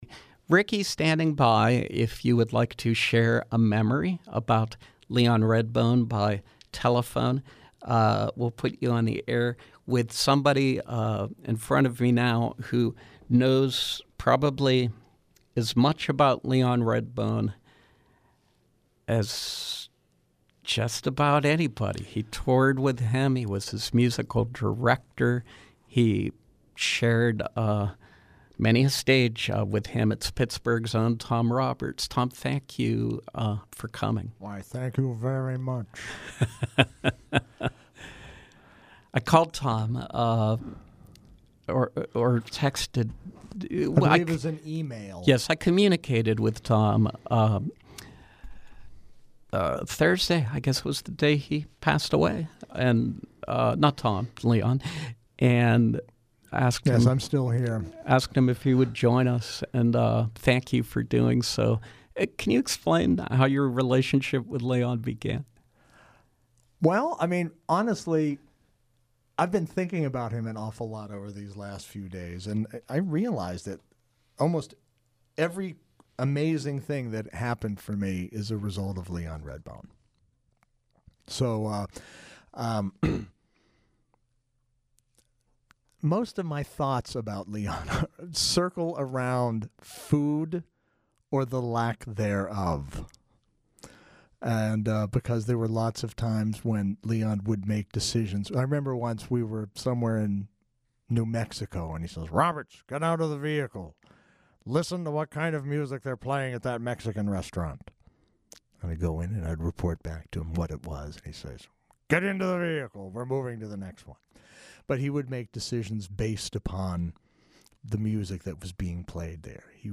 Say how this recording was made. In Studio Popup